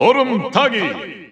The announcer saying Ice Climbers' names in Korean releases of Super Smash Bros. Ultimate.
Ice_Climbers_Korean_Announcer_SSBU.wav